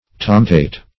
Tomtate \Tom"tate\, n.
tomtate.mp3